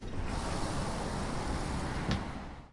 有轨电车" 5门打开 ext
描述：门在外面打开